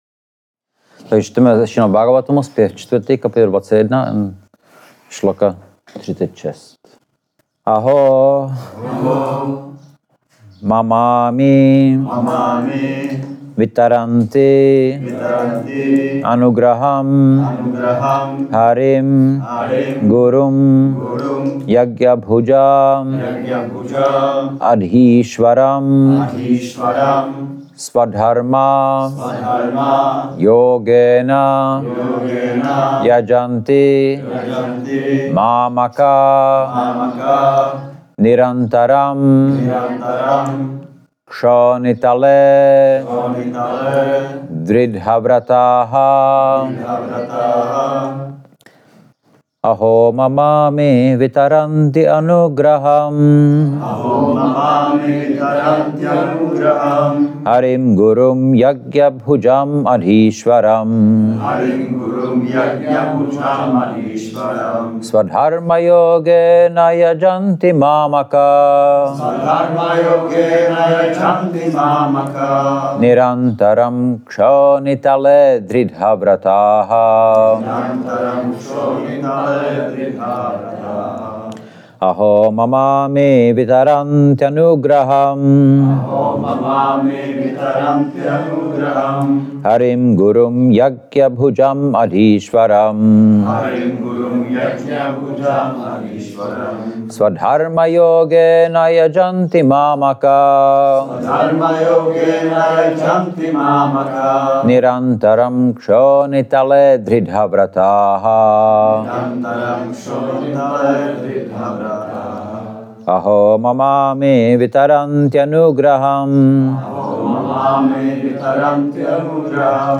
Šrí Šrí Nitái Navadvípačandra mandir
Přednáška SB-4.21.36